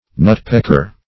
\Nut"peck`er\